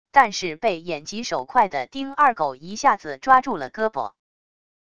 但是被眼疾手快的丁二狗一下子抓住了胳膊wav音频生成系统WAV Audio Player